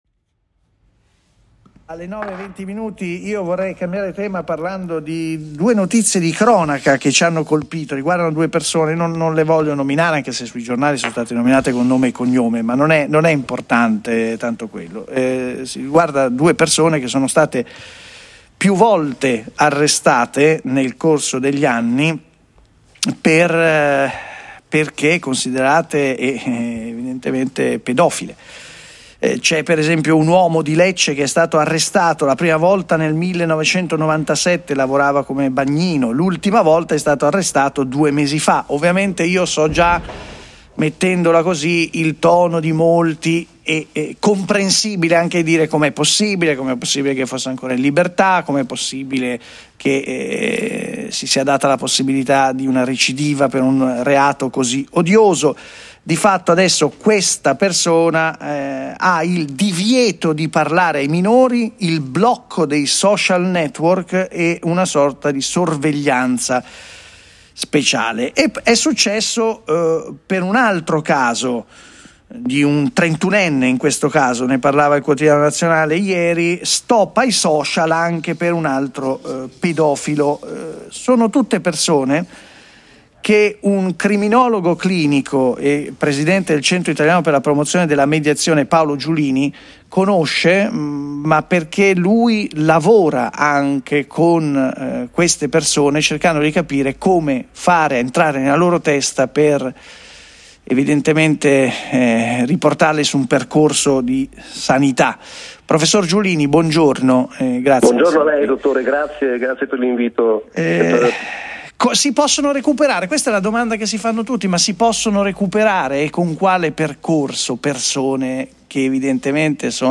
Condividiamo con piacere l’intervista integrale